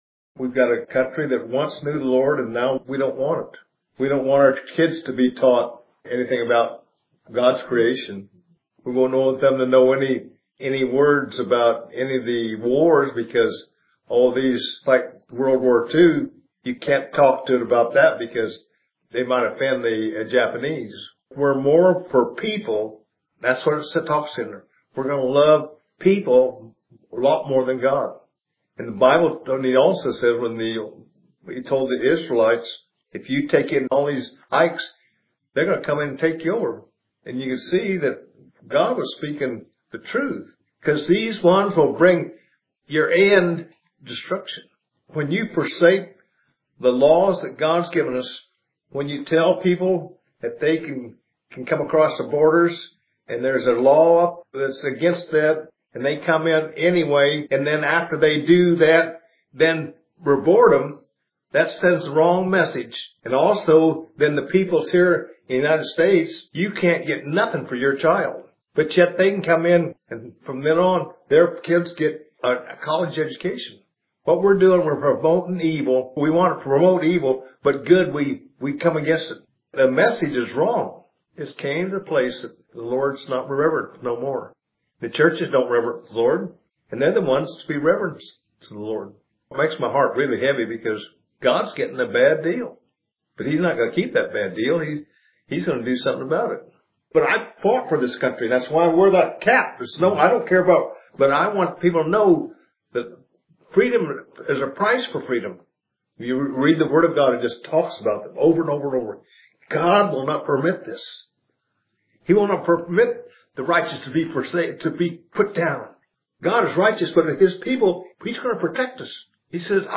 Audio Recording Prophetic , Revelation , Teaching Comments Off on We Cut Off God’s Right Arm!